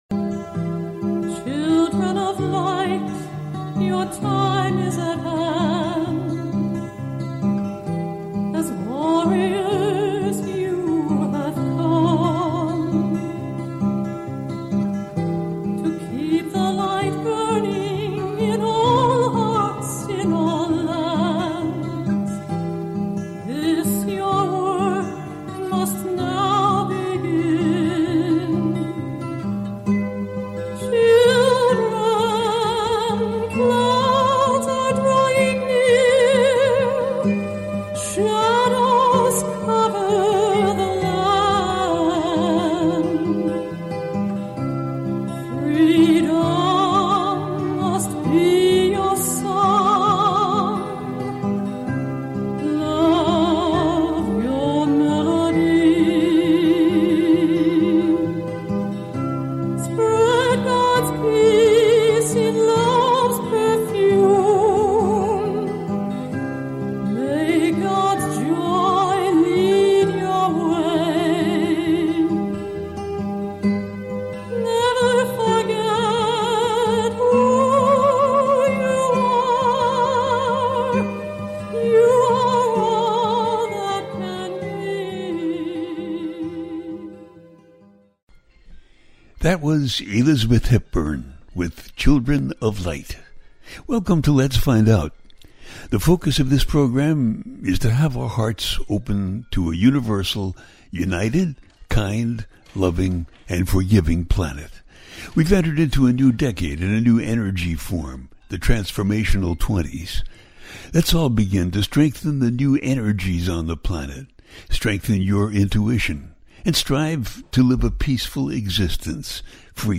Jupiter Retrogrades in Gemini - A teaching show